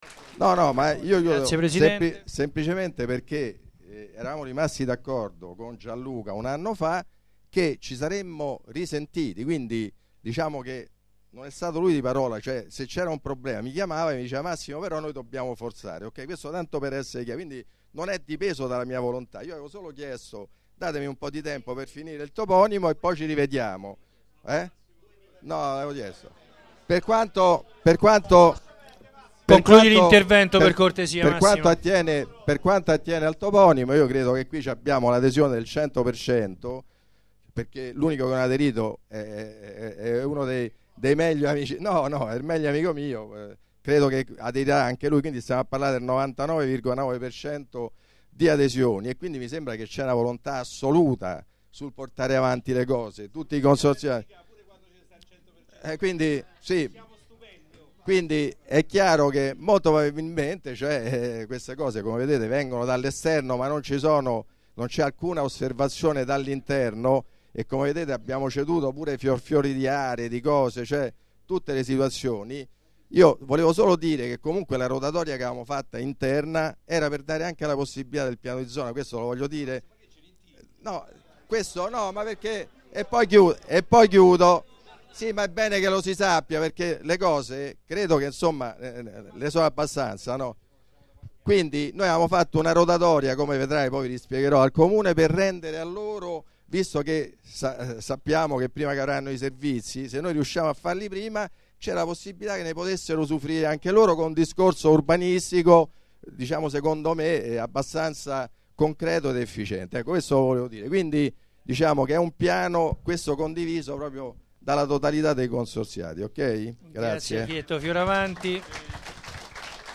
Assemblea
Registrazione integrale dell'incontro svoltosi il 3 aprile 2013 presso la sala consiliare del Municipio Roma XIX